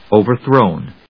音節o・ver・thrown 発音記号・読み方
/òʊvɚθróʊn(米国英語), `əʊvəθrˈəʊn(英国英語)/